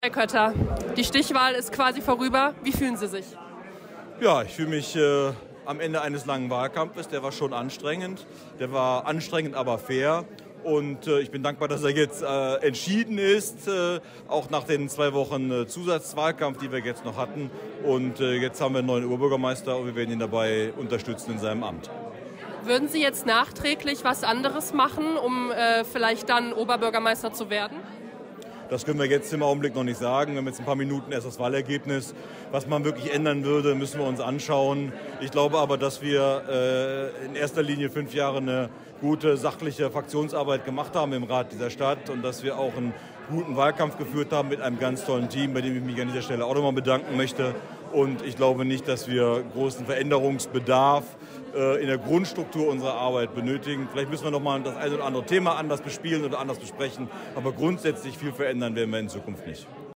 Die Stimmen der Gewinner und Verlierer